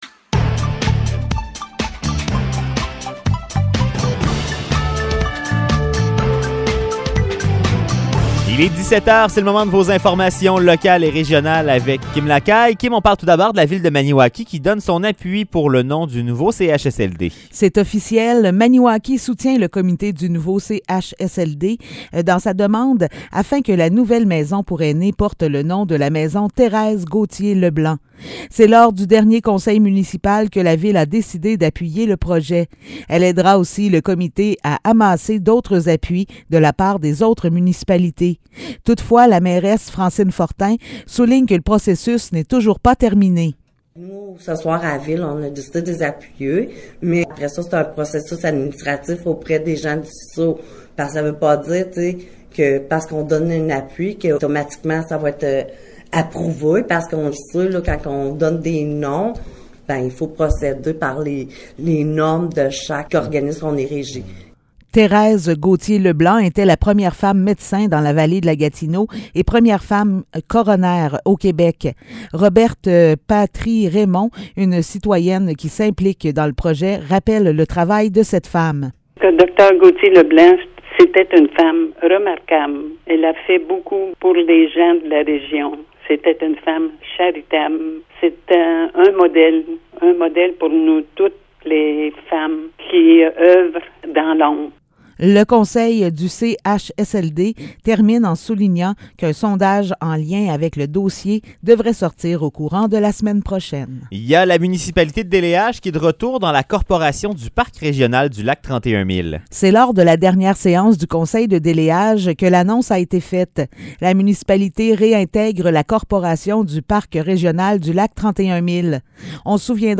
Nouvelles locales - 11 avril 2022 - 17 h